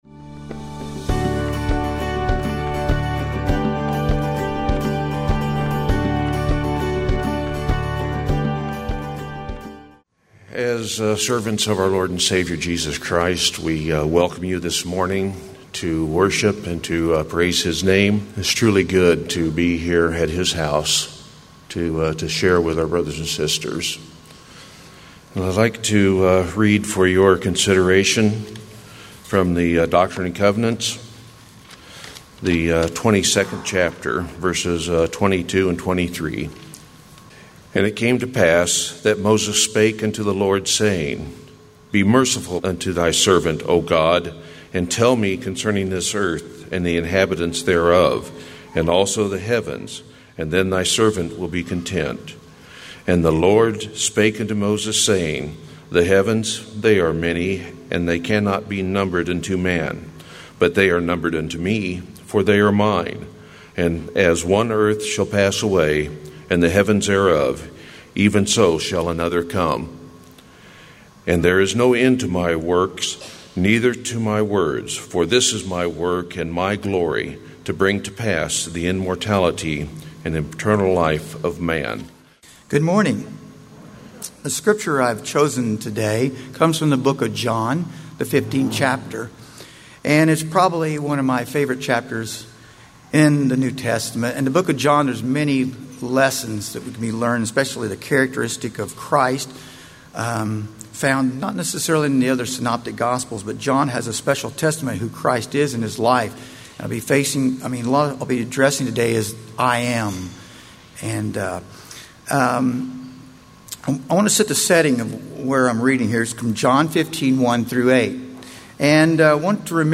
The Church Of Jesus Christ Living Hope Restoration Branch Independence, Missouri
“The True Vine” Worship Service September 8th